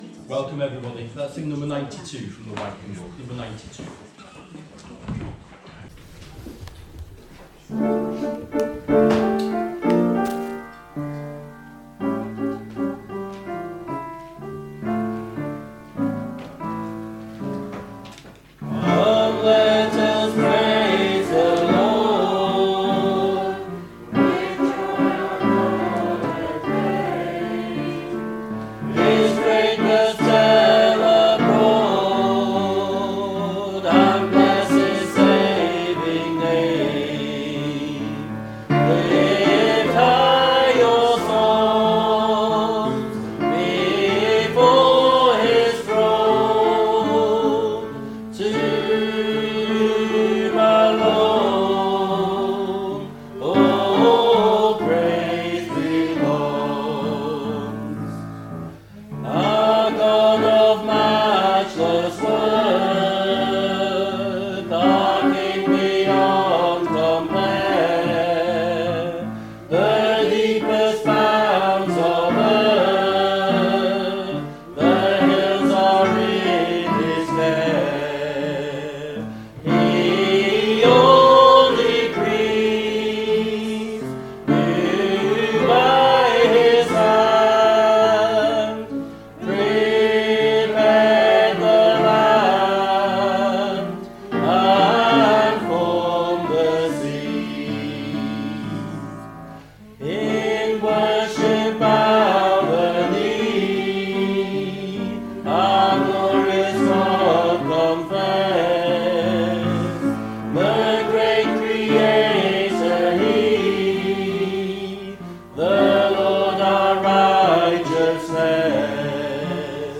Below is audio of the full service.
2025-10-12 Evening Worship If you listen to the whole service on here (as opposed to just the sermon), would you let us know?